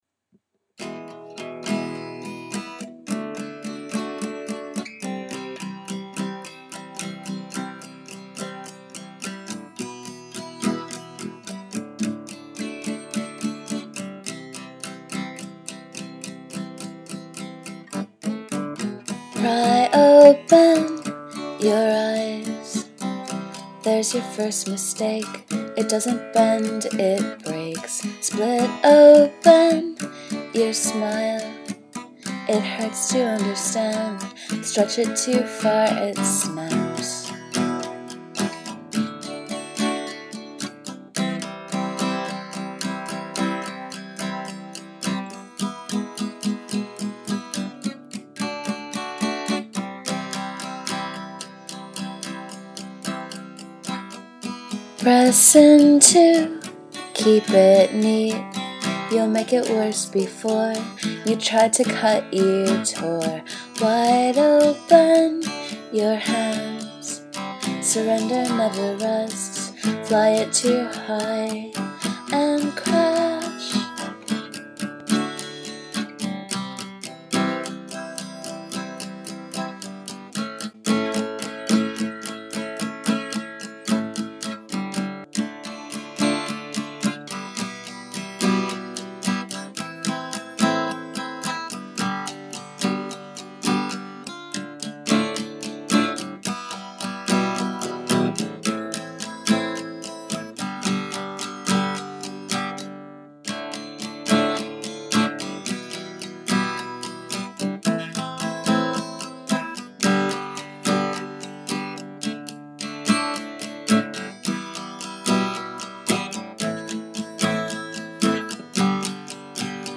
E D C A
Dm Em Am G
instr Vx2
vox Vx2
another train song. this one used to have a chorus but the chorus was really bad so i took it out. maybe will put something else in or else make this electrelane-aspiring droning or something like that.